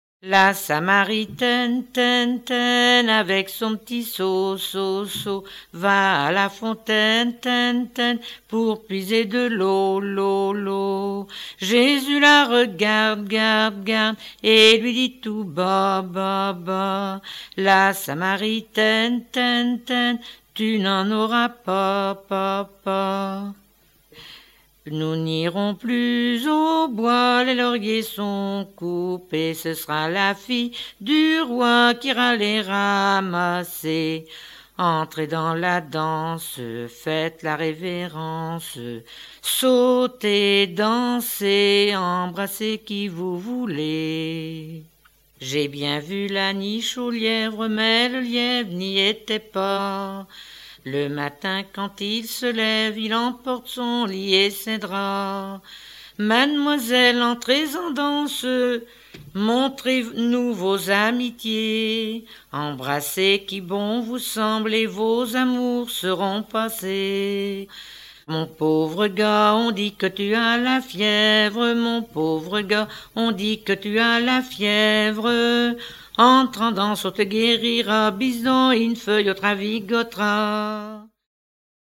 Suite de refrains de rondes enfantines
rondes enfantines (autres)